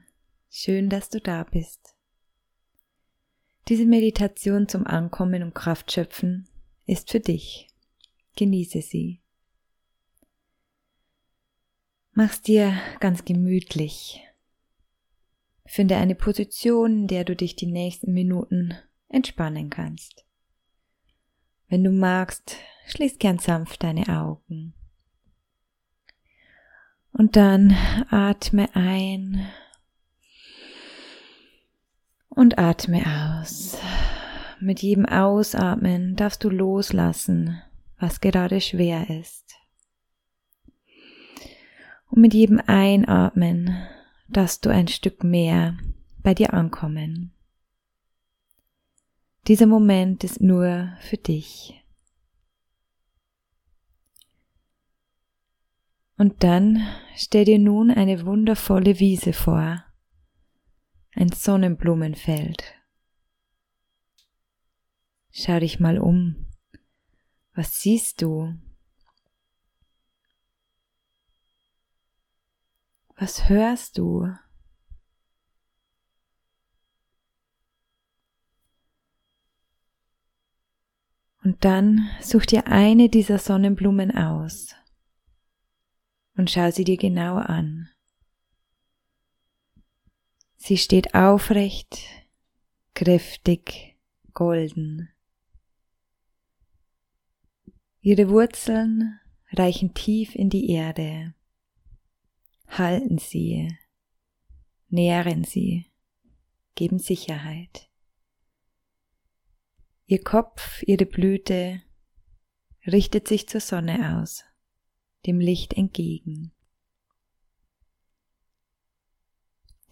Diese Visualisierung ist für dich – um anzukommen, Kraft zu tanken und dich selbst zu spüren.